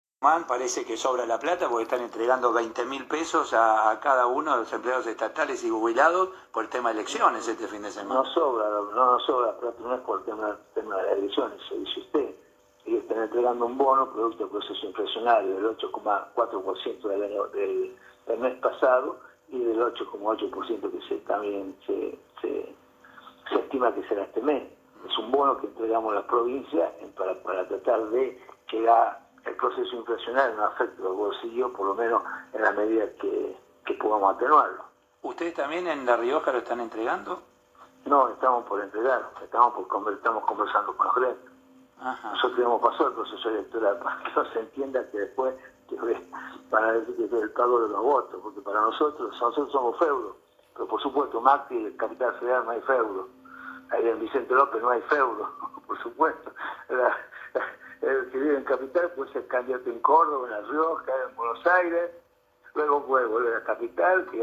Ricardo Quintela en diálogo con radio Continental
En el caso riojano, el gobernador Ricardo Quintela aseguró en una entrevista que le realizaron en un programa de radio Continental, que «La Rioja entregará un bono a los trabajadores de la administración pública».